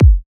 SOUTHSIDE_kick_super_club.wav